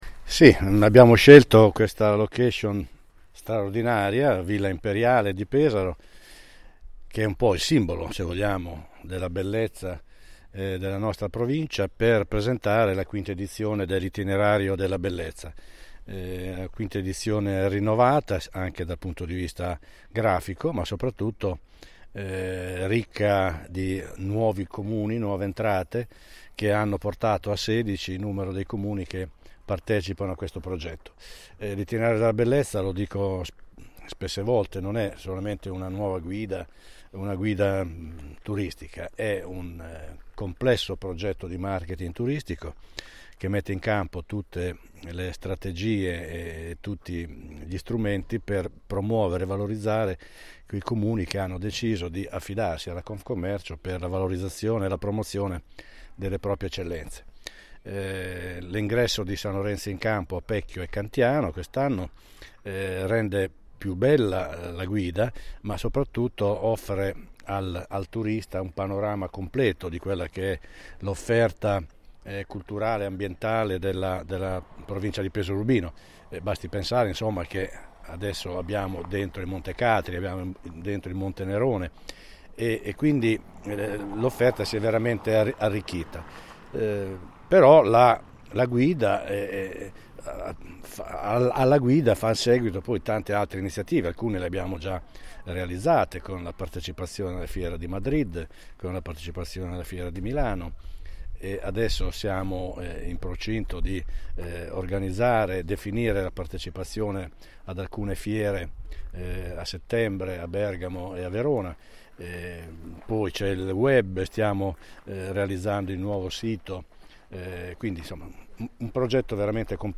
Nella splendida cornice di Villa Imperiale di Pesaro, è stato presentato il nuovo Itinerario della Bellezza 2022 di Confcommercio Marche Nord.